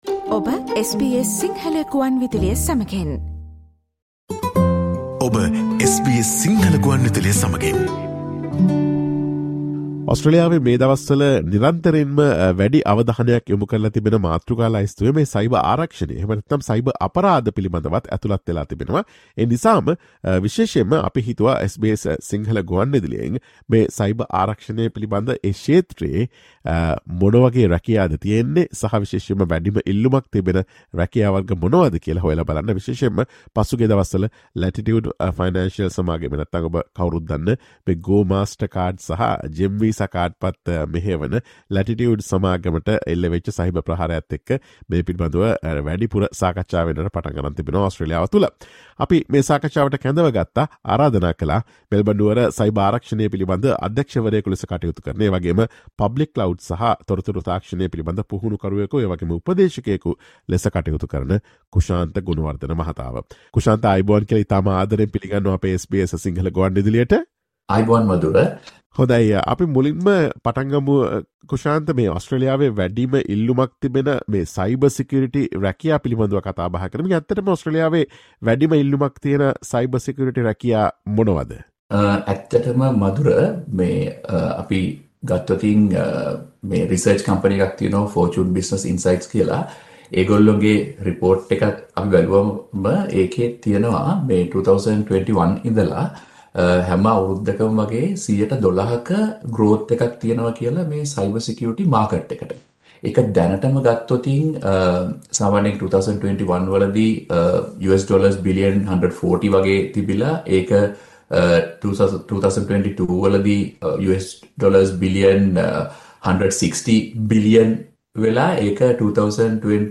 Listen to SBS Sinhala Radio's discussion on the most in-demand Cybersecurity jobs in Australia, their salaries, and the basic educational qualifications and additional certifications.